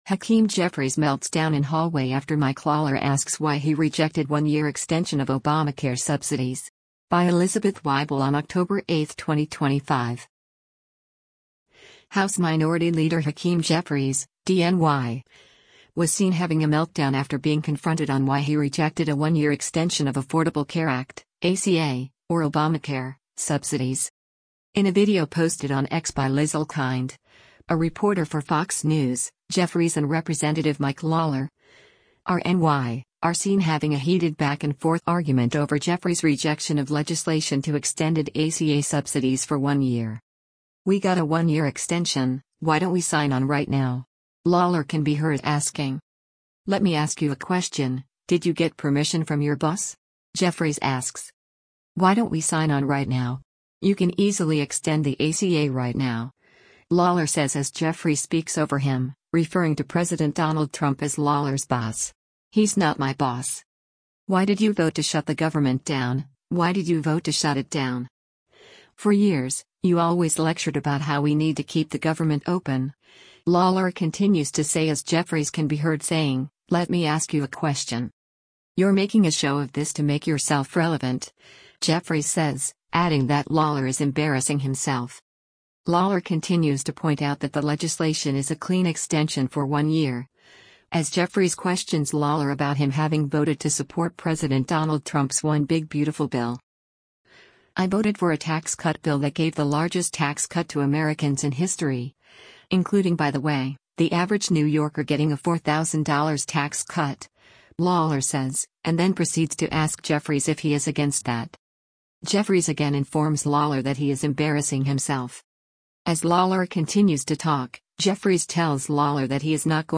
“We got a one-year extension, why don’t we sign on right now?” Lawler can be heard asking.
As Lawler continues to talk, Jeffries tells Lawler that he’s “not going to talk” to him and “talk over” him because he doesn’t “want to hear” what Jeffries has to say.